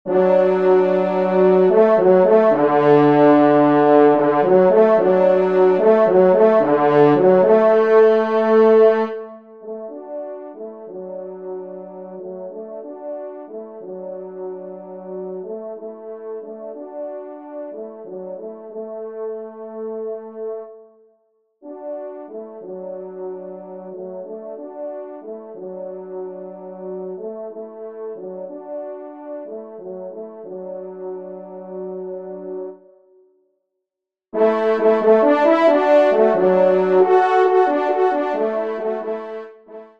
Pupitre 2°Trompe